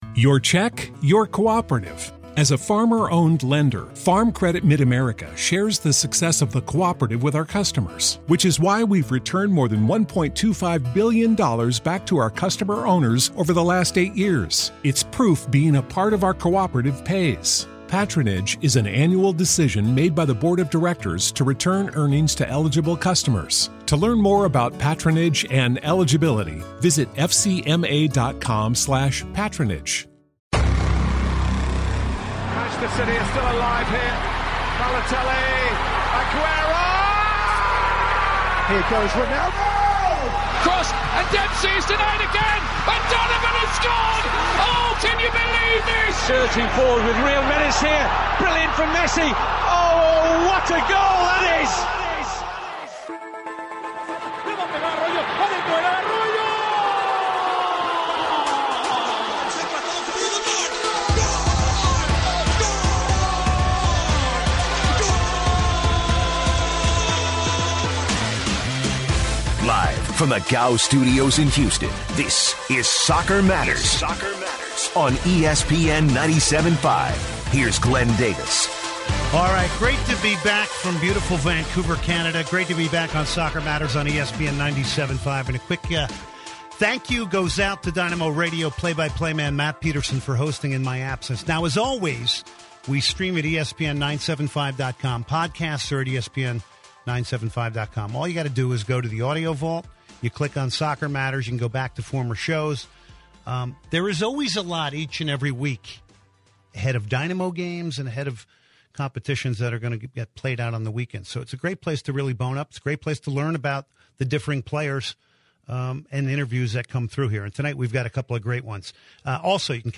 Plus callers and more!